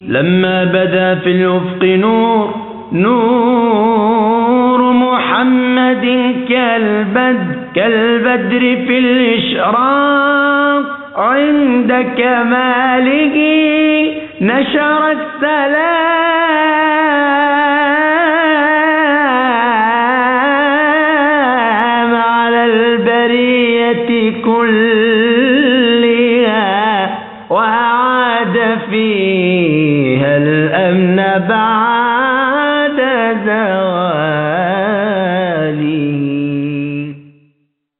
اناشيد